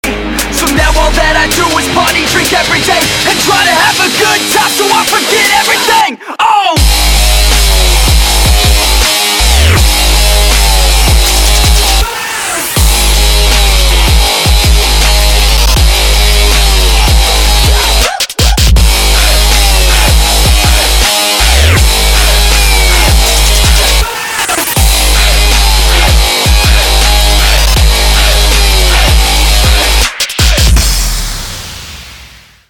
• Качество: 160, Stereo